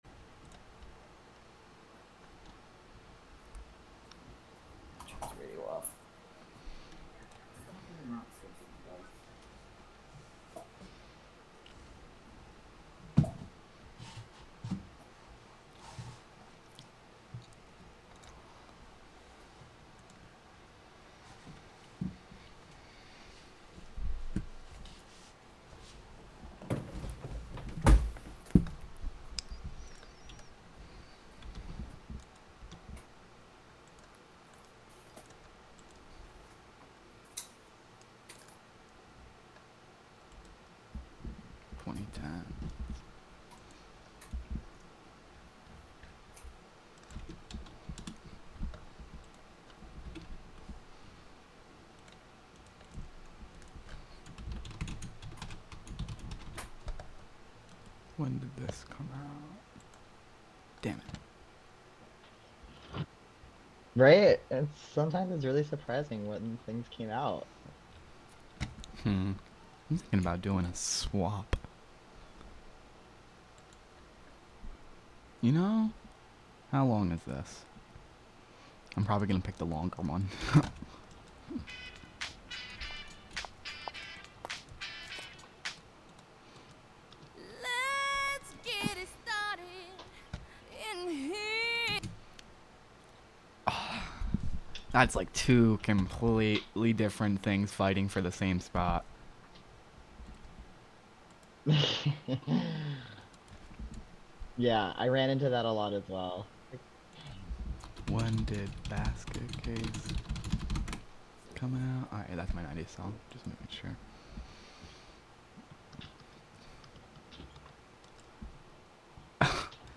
Live from the Catskill Clubhouse.
Chill music day, a look back on the music from the 80's all the way to present day Play In New Tab (audio/mpeg) Download (audio/mpeg)